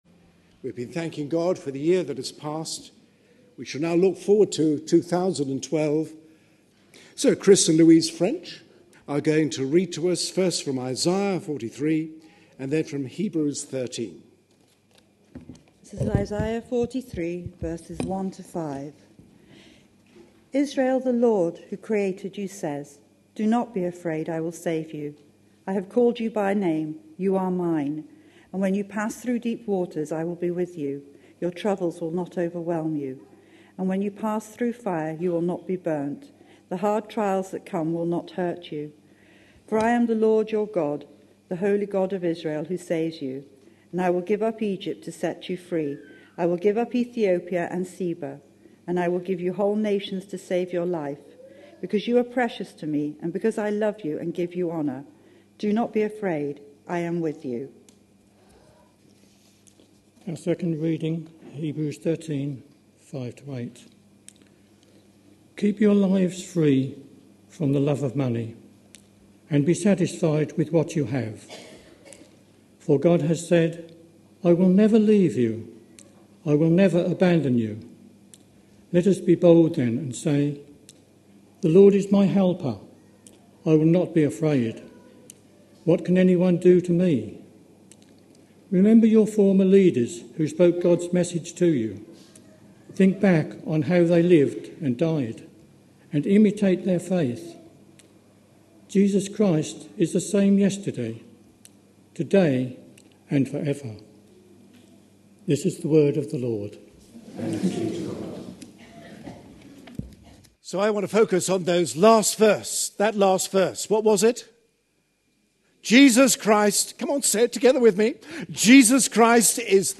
A sermon preached on 1st January, 2012.